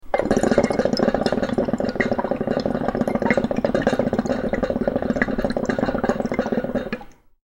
Звуки кальяна
Медлительный звук кальяна